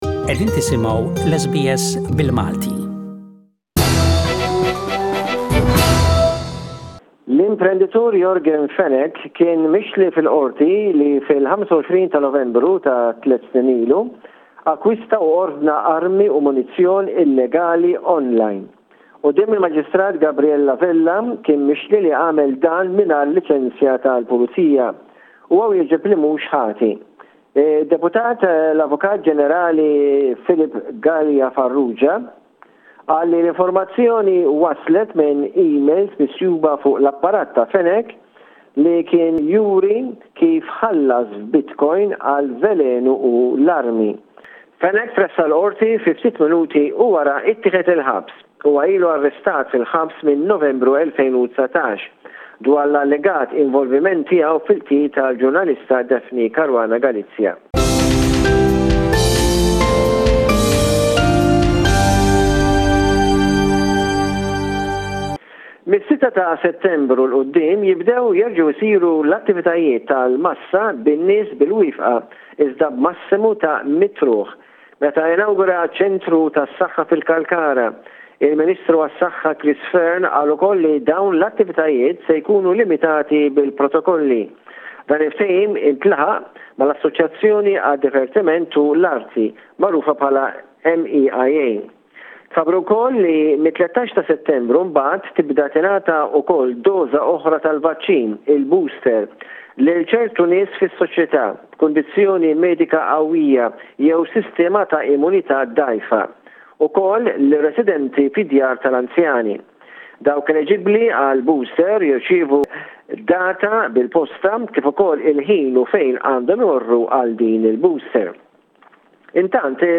news report from Malta